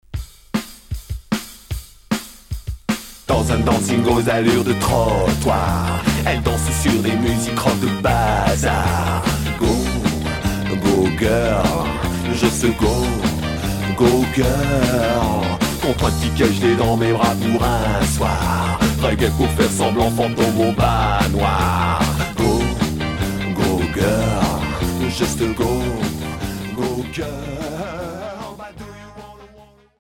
Ska Premier 45t retour à l'accueil